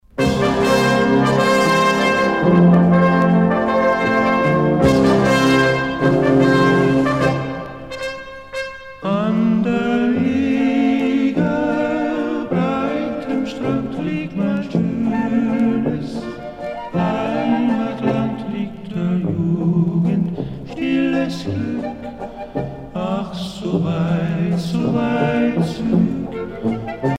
danse : valse lente
Pièce musicale éditée